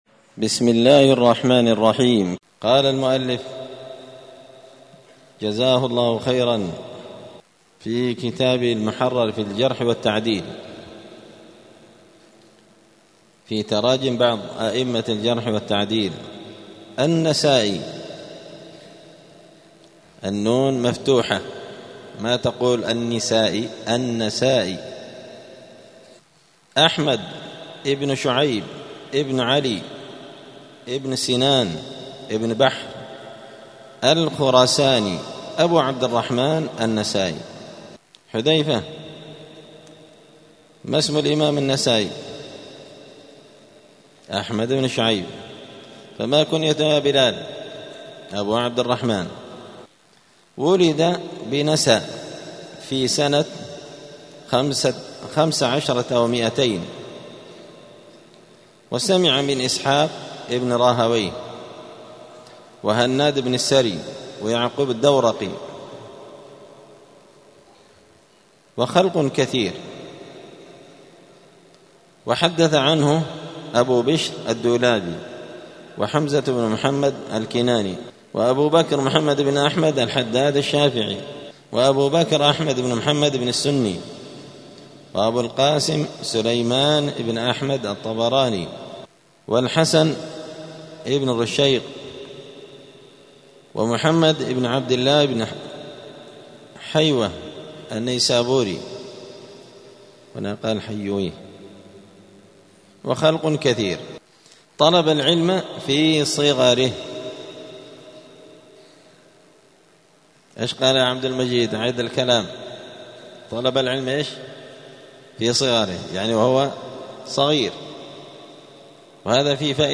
*الدرس السادس والتسعون (96) باب التعريف بالنقاد الإمام النسائي*